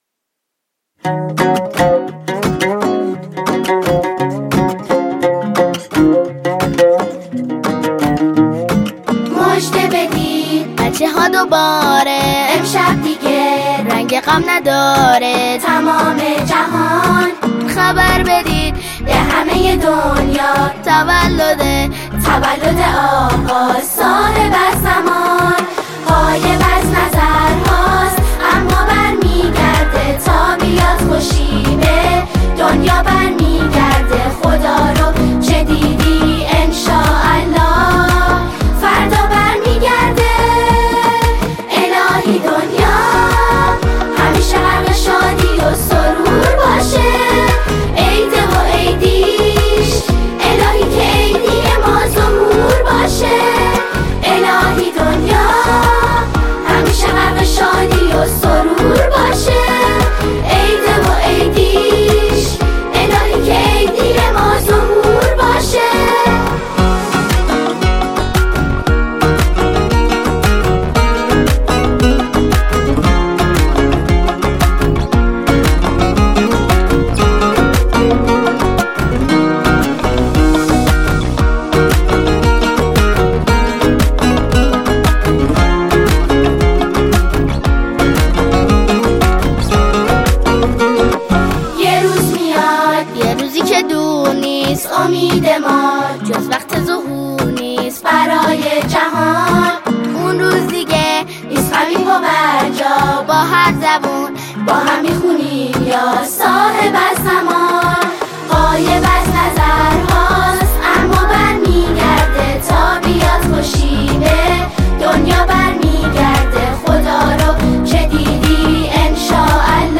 این اثر به صورت جمعخوانی به مراحله اجرا درآمد.